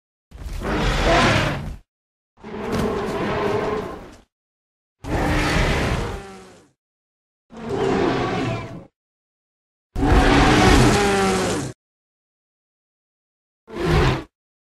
Звуки мамонта
Громкий звук сопротивления среди мамонтов